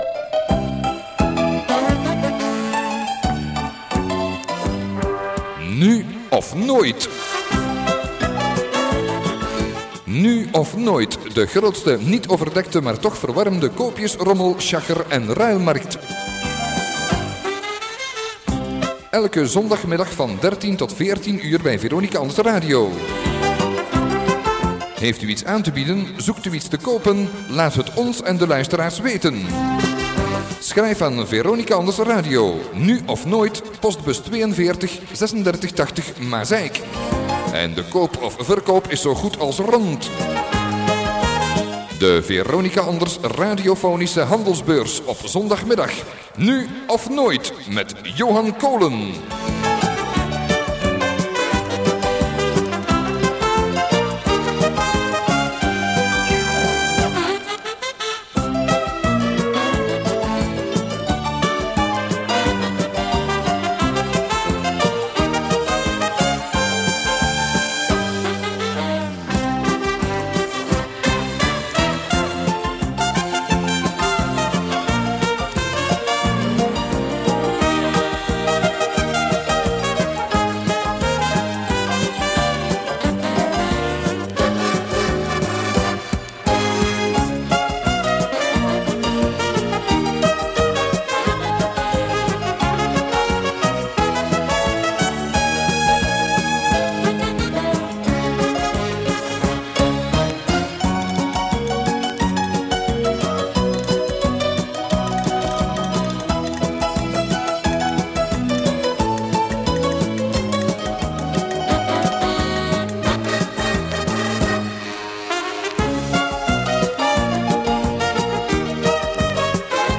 Begintune